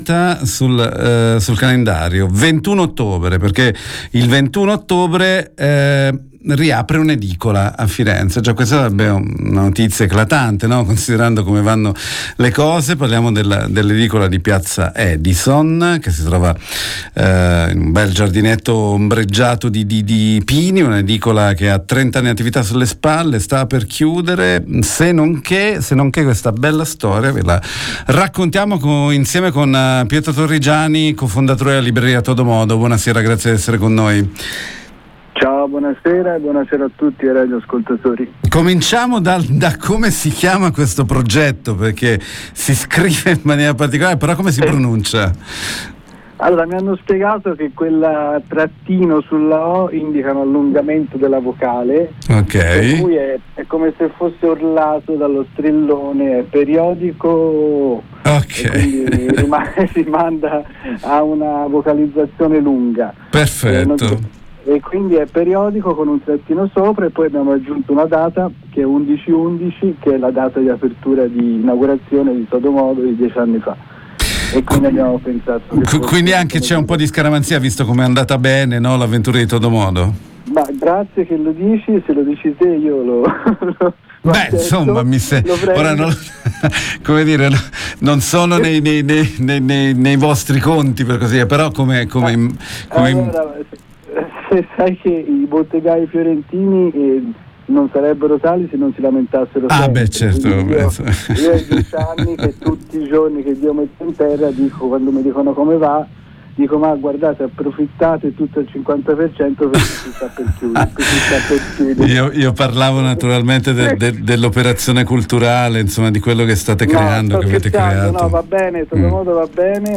periodico 11/11Inaugurazione il 21 ottobre: la storica edicola di piazza Edison diventa anche uno spazio d’aggregazione con iniziative culturali e non solo. Intervista